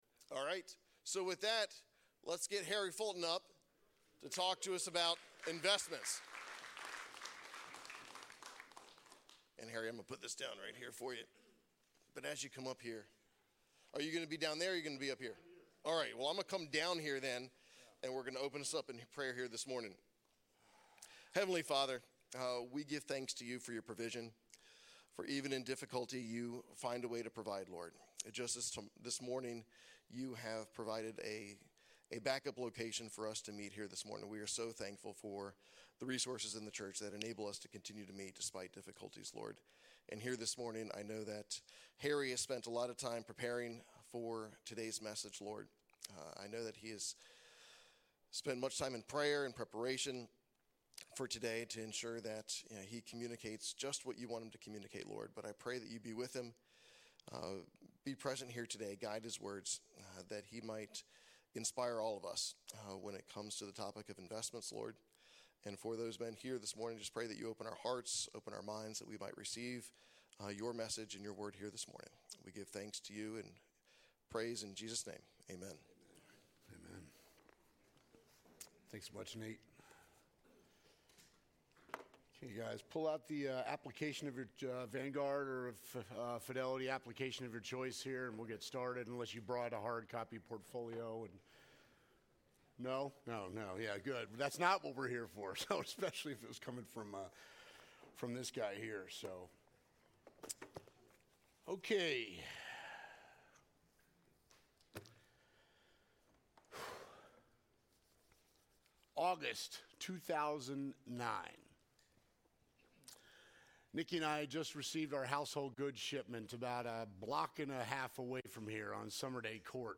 2025 Burke Community Church Lesson